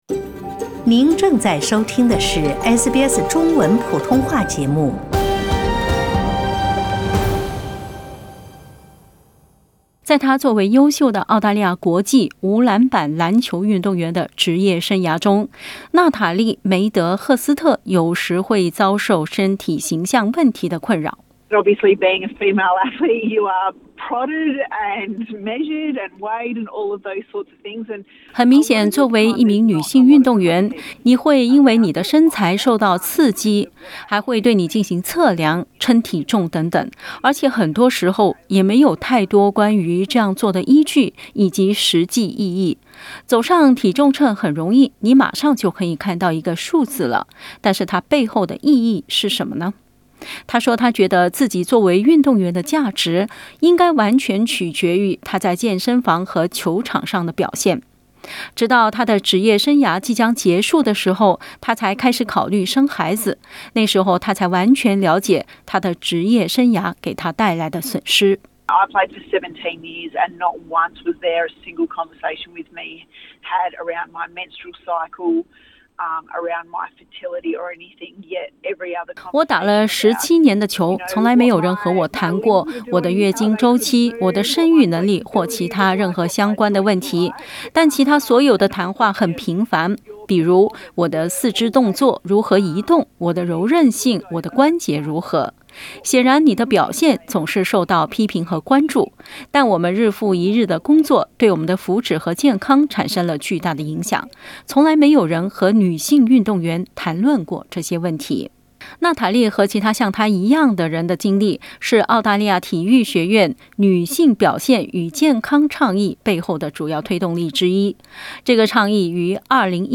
（點擊圖片收聽報道）